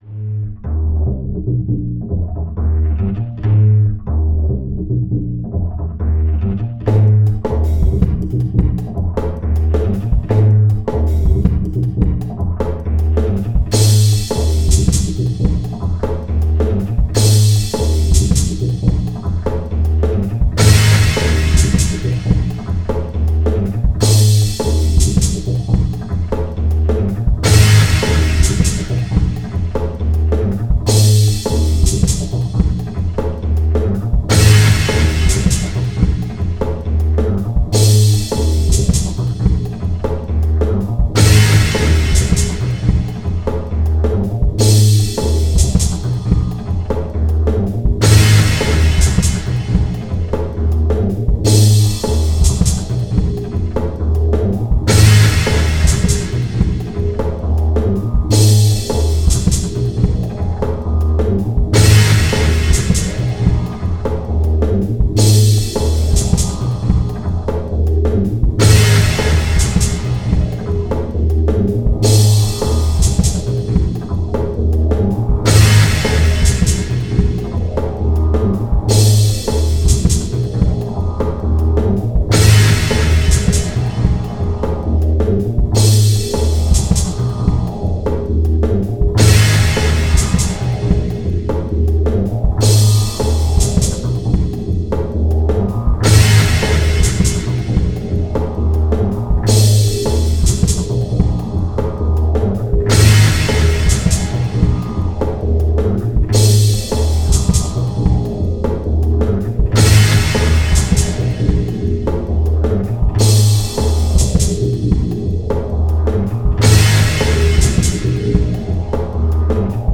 Some days you wake up with a bass riff in your head and it just won’t go away.
In the end I cobbled a few samples and loops and this was the result.
…and yes, that is a very over-driven bass; but it took some tweaking to get the effect I heard.